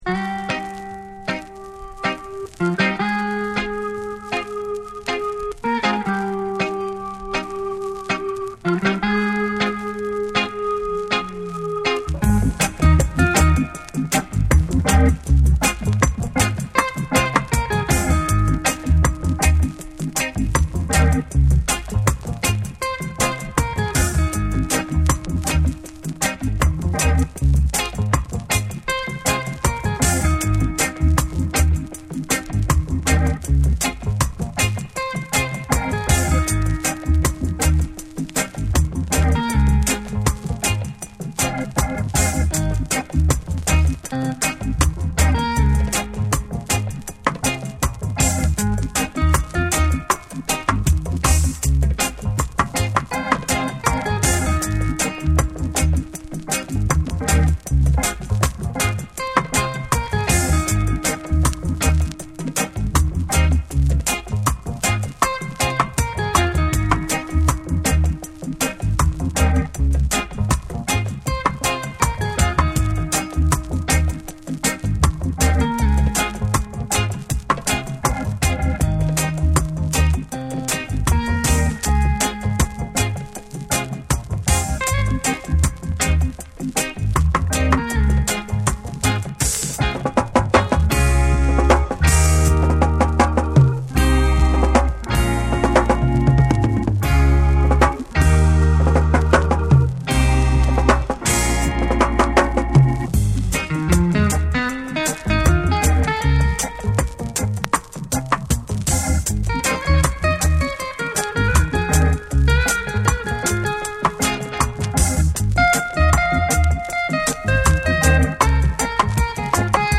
名曲たちが深いエコーと重量級リズムで再構築された
スモーキーなダブ処理と空間的なミックスで別次元の魅力を放つ好内容！
※チリノイズあり。